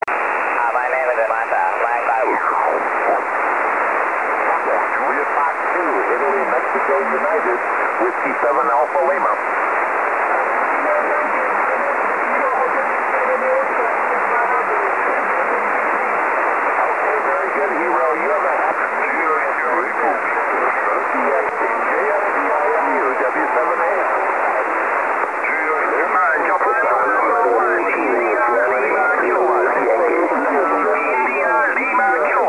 6m2(MP3 317KB)　　 SSB でWを呼んでいる状況。まだコンディションが最高潮になる前で若干弱い